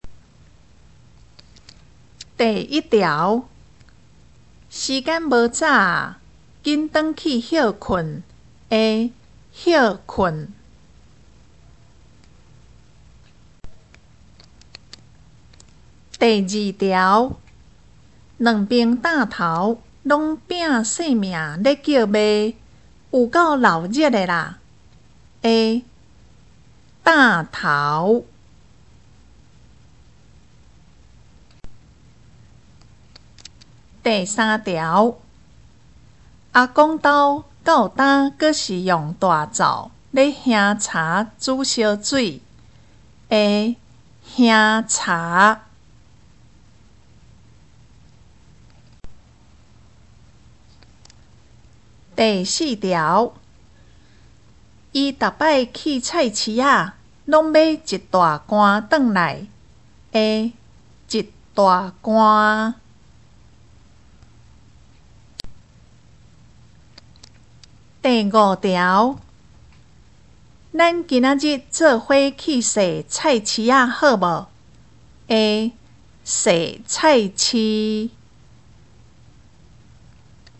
113上國中B1單元3聽力測驗.mp3